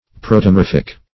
Search Result for " protomorphic" : The Collaborative International Dictionary of English v.0.48: Protomorphic \Pro`to*mor"phic\, a. [Proto- + Gr. morfh` form.]